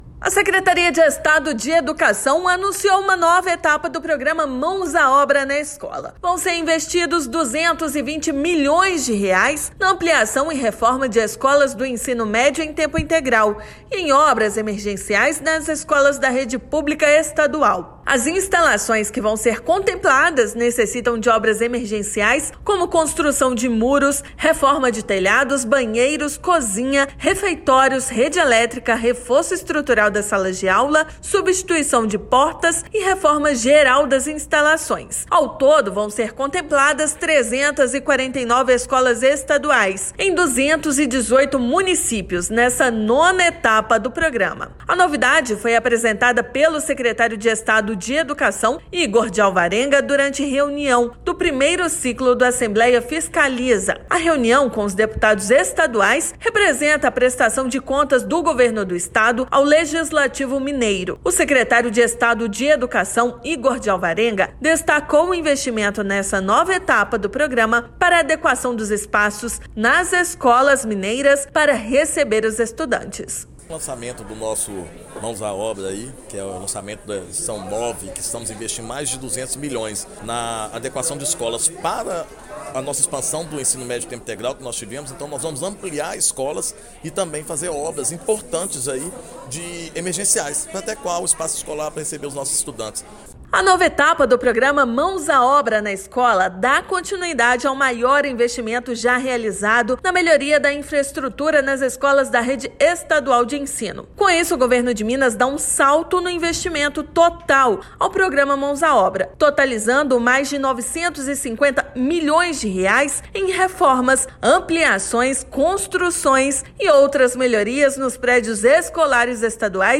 Investimento de R$ 220 milhões contemplará intervenções emergenciais e ampliação de escolas do Ensino Médio em Tempo Integral. Ouça matéria de rádio.